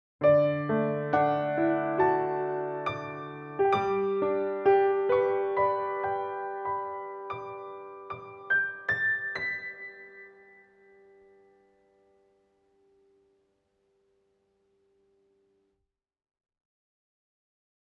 コメント 短いピアノソロのジングルです。 リラックス、ゆったり、穏やかといったイメージで作りました。